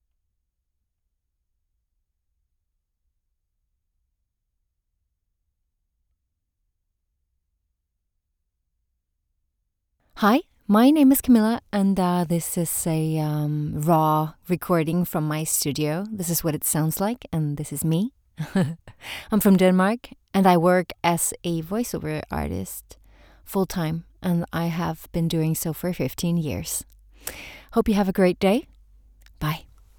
Female
TEENS, 20s, 30s, 40s
Authoritative, Bubbly, Character, Corporate, Friendly, Natural, Soft, Upbeat, Versatile, Wacky, Young
Voice reels
Microphone: Neumann TLM 102 and AKG C214